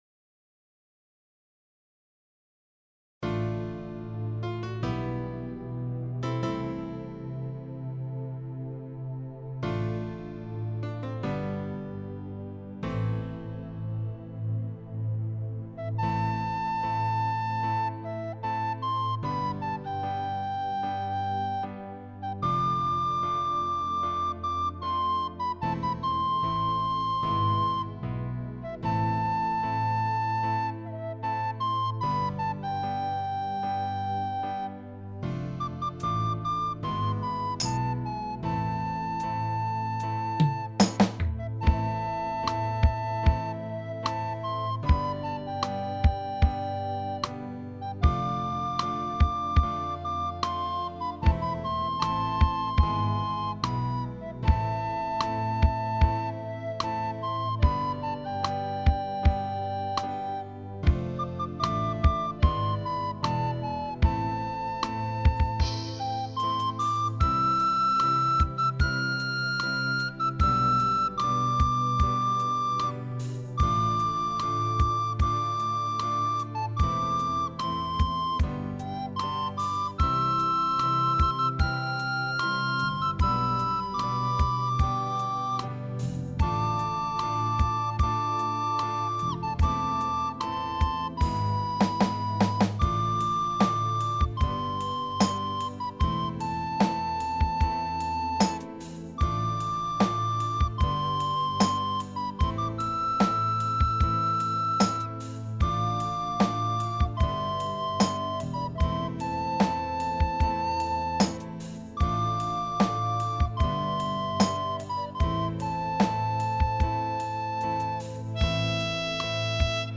ביצוע חדש ואיכותי של המנגינה שהלחנתי.
מצטער אבל רק תופים הקלטתי מאורגן, וזה הכניס המור רעש רקע...